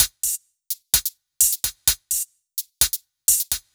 Index of /musicradar/french-house-chillout-samples/128bpm/Beats
FHC_BeatA_128-02_HatClap.wav